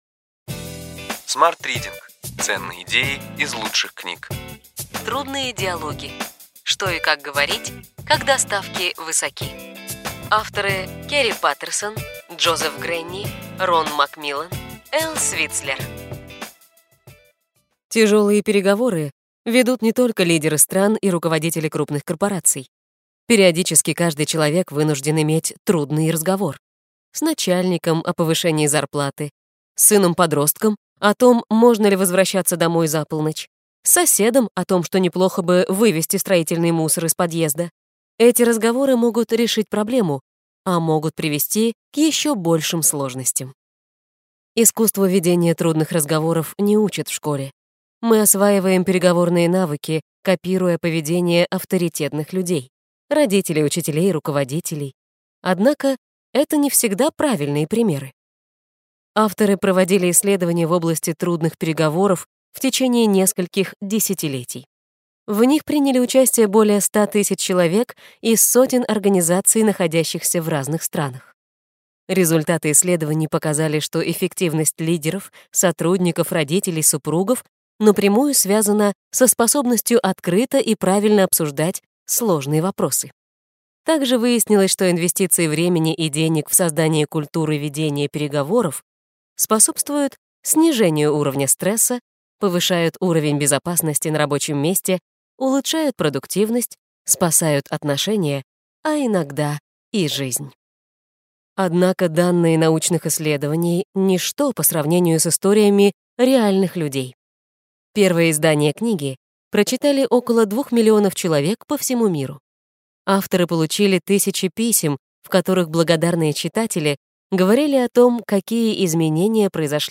Аудиокнига Ключевые идеи книги: Трудные диалоги.